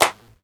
• Hand Clap G# Key 12.wav
Royality free clap - kick tuned to the G# note. Loudest frequency: 2884Hz
hand-clap-g-sharp-key-12-Iab.wav